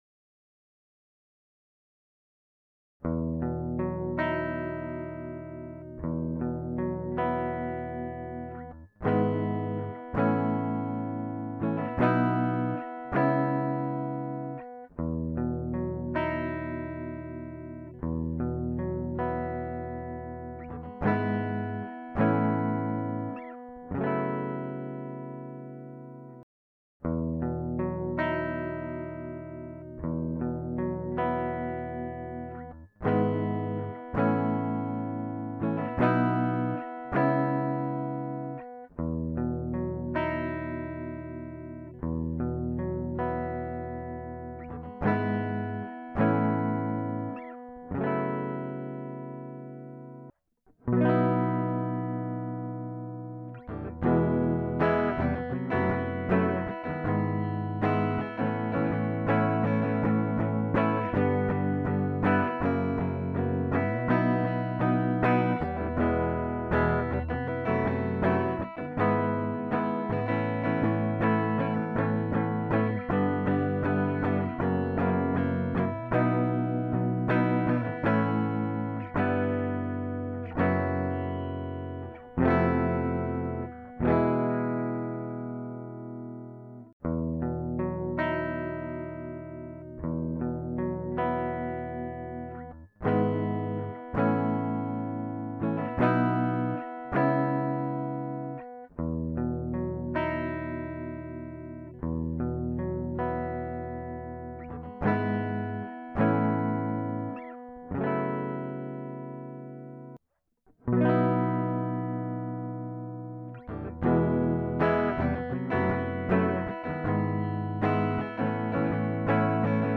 ist irgendwie singer-songwriter
Die Begleitgitarre könnte ein bisschen heller klingen............und vielleicht einen Ticken leiser. Die Solo-Gitarre vielleicht ein bisschen mehr Weite um sich von der anderen abzuheben. den Gesang find ich perfekt.
Ja, die Vocals sind sehr schwer in den Griff zu bekommen aufgrund des Nahbesprechungseffekts , die Hintergrundgeräusche sind auch immens.....